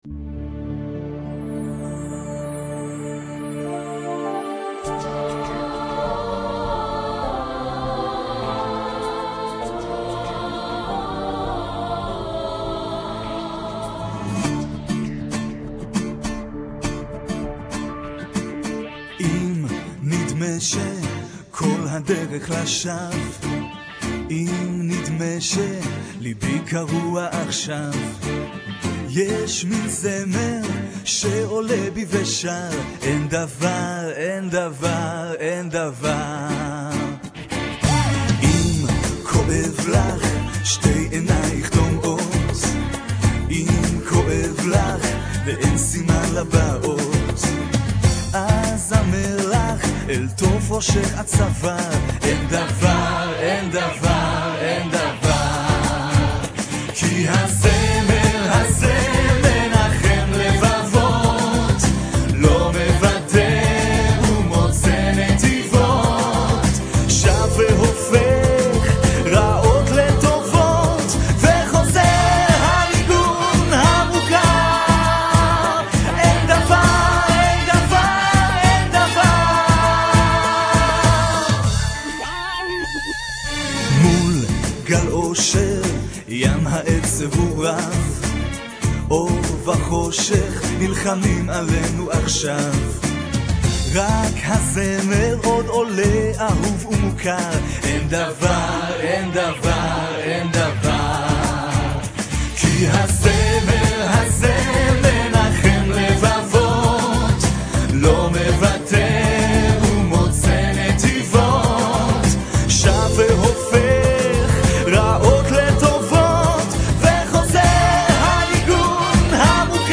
Copenhagen, Denmark